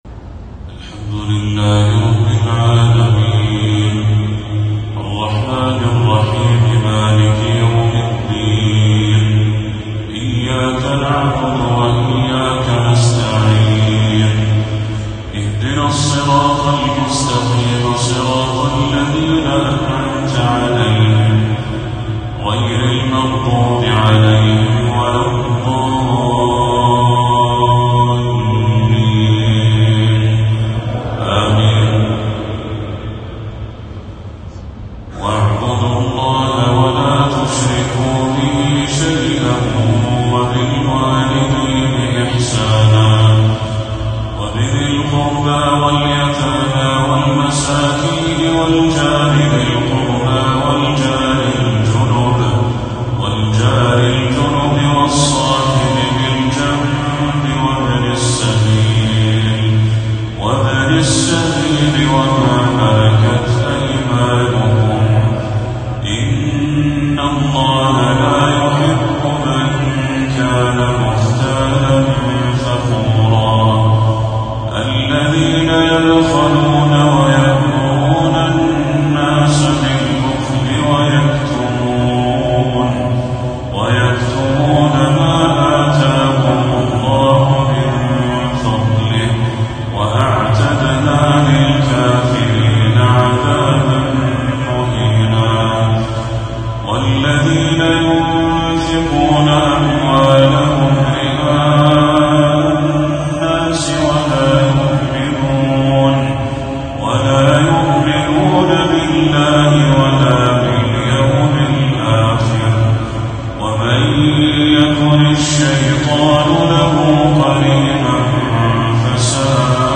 تلاوة بهيِّة من سورة النساء للشيخ بدر التركي | عشاء 9 صفر 1446هـ > 1446هـ > تلاوات الشيخ بدر التركي > المزيد - تلاوات الحرمين